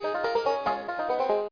rattle02.mp3